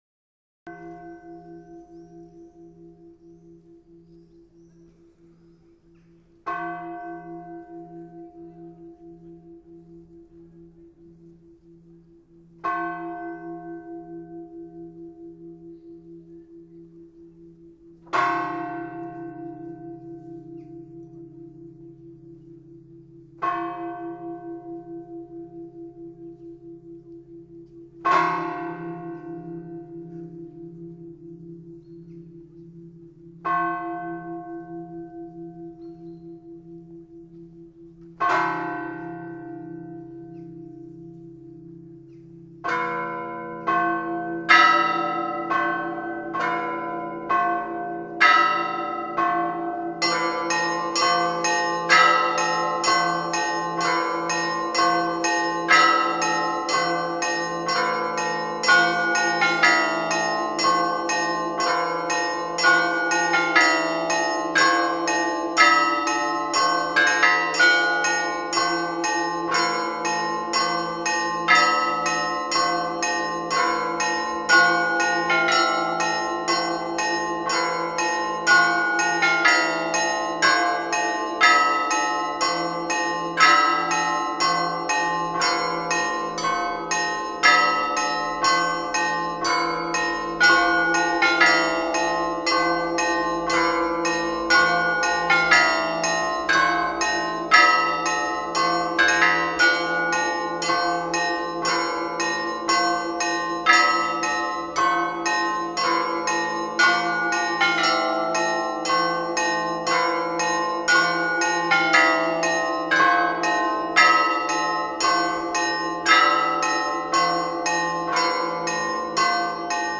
02_Monastyrskij_Zvon.mp3